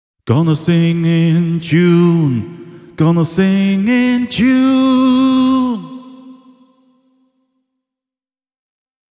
Mikrofonietuaste on hyvin laadukas, ja jo pelkästään kaiku kuulostaa muhkealta:
Enhance-osaston laululle optimoidun kompressorin ja EQ:n ansiosta laulusoundista tulee sopivasti viimeistelty: